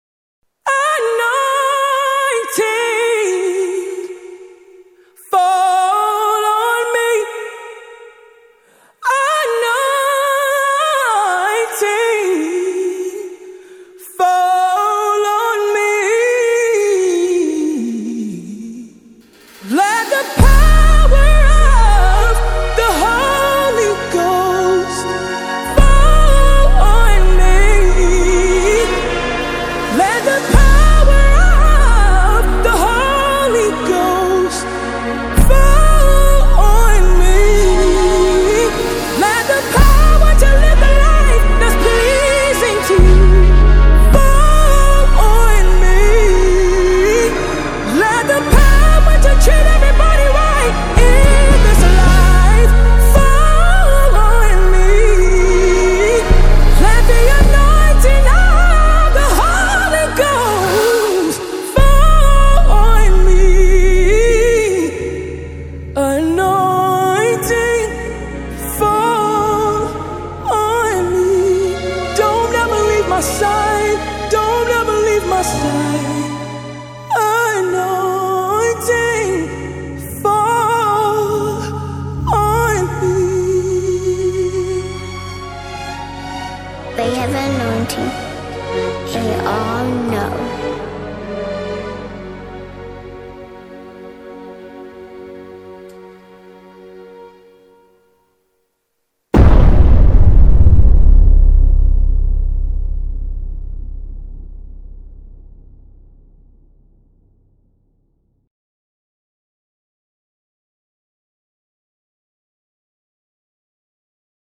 September 7, 2024 admin Gospel, Music 0
worship single
gospel song